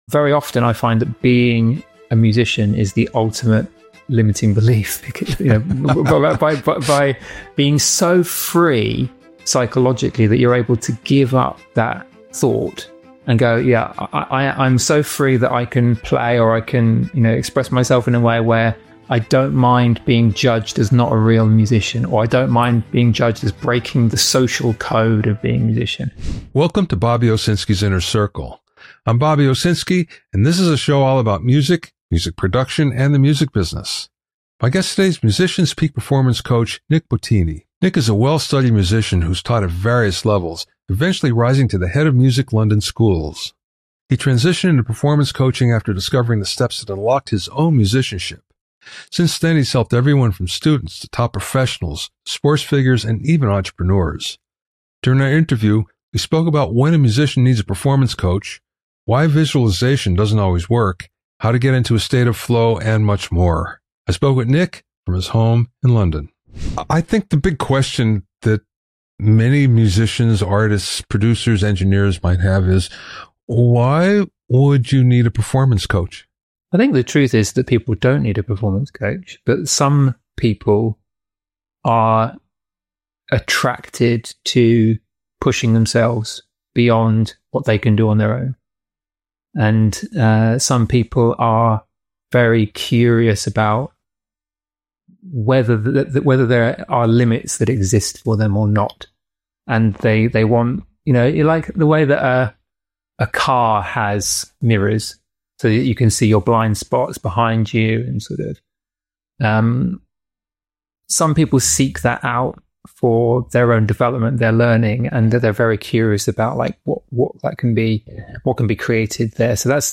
Interviews with music industry movers and shakers, tips, and news.